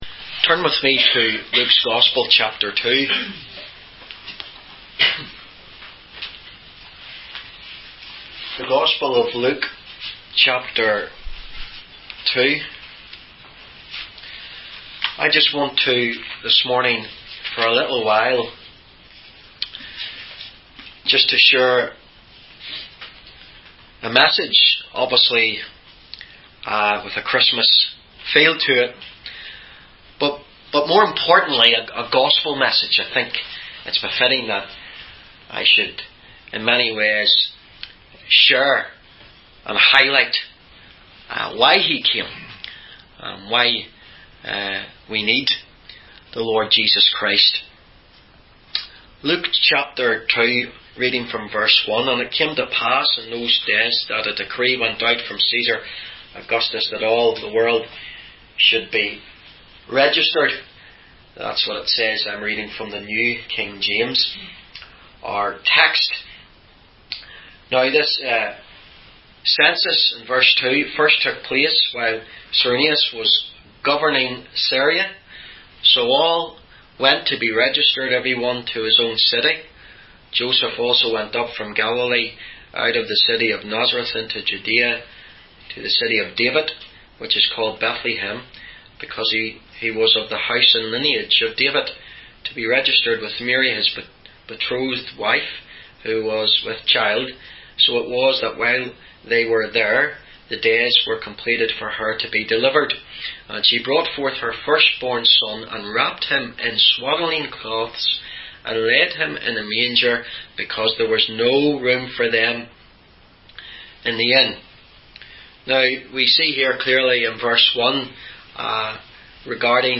In this sermon, the preacher emphasizes the importance of choosing to serve God and accepting Jesus as the Savior. He highlights the need for mankind to be saved and redeemed, and expresses gratitude for God sending His Son to fulfill this purpose. The preacher encourages memorizing Luke 19:10, which states that Jesus came to seek and save the lost.